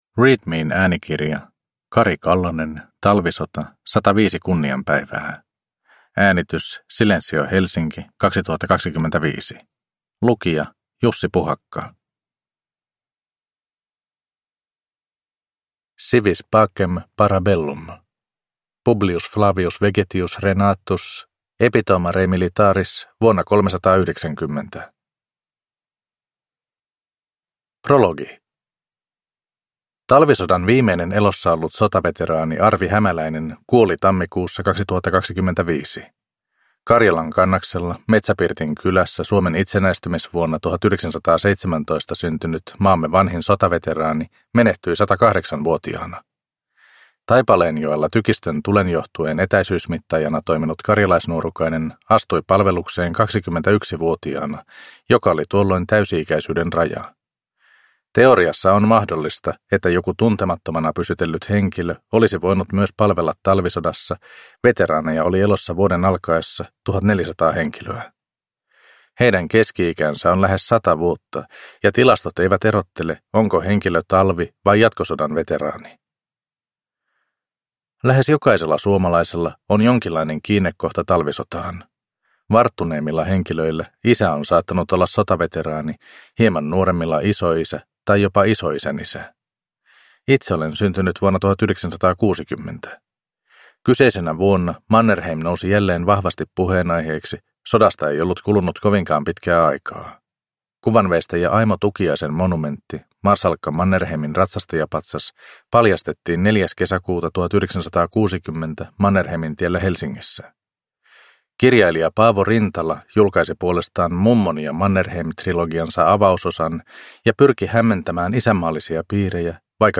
Talvisota - 105 kunnian päivää – Ljudbok